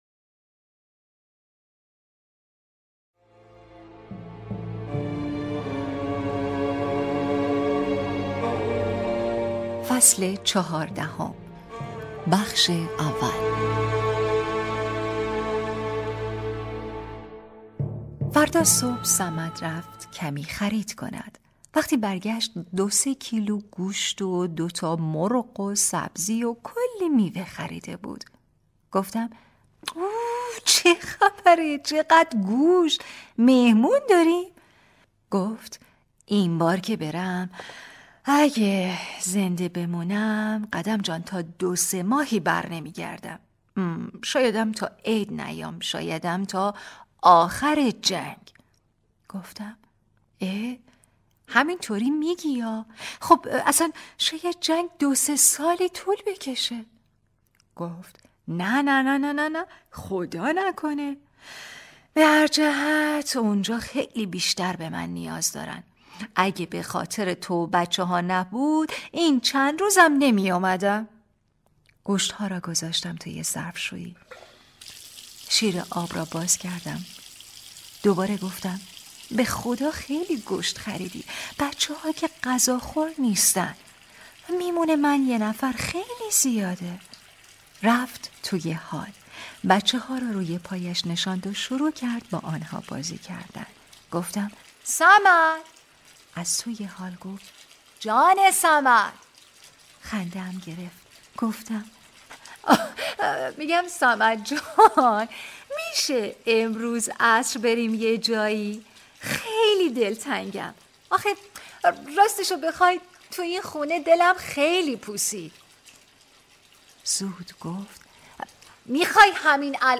کتاب صوتی | دختر شینا (11)
روایتگری